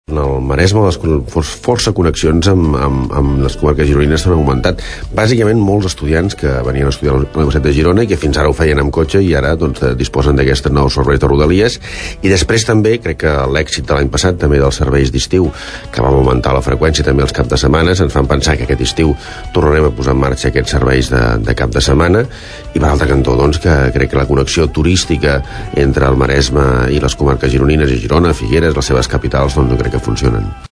El Departament de Territori i Sostenibilitat ha valorat molt positivament les xifres d’aquest primer any de l’RG1 i ha destacat que un 30% dels viatges tenen origen o destinació algunes de les estacions de la Selva Litoral o el Maresme que fins aleshores no tenien connexió directa amb la resta d’estacions de la línia Girona-Figueres-Portbou. Ho explica el secretari de territori i mobilitat, Ricard Font.